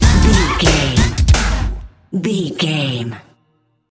Aeolian/Minor
D
drum machine
synthesiser
90s
Eurodance